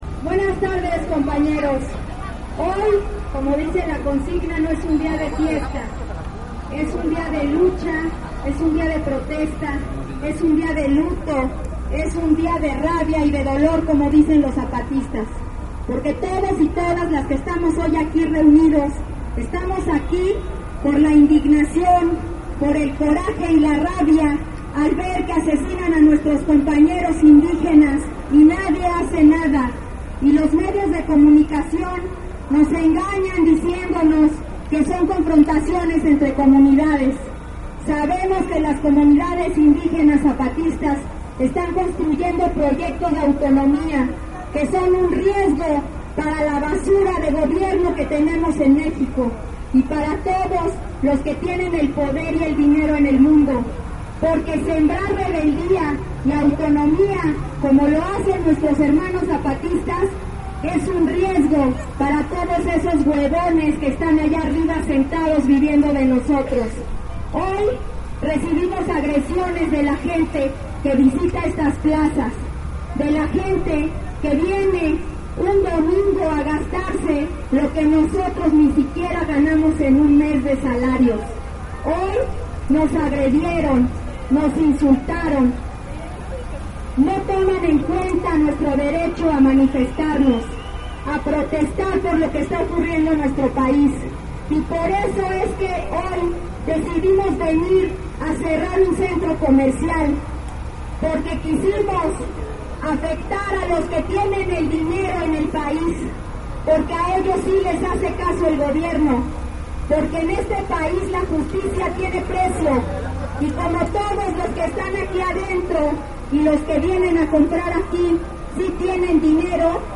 Palabras del FPFVI-UNOPII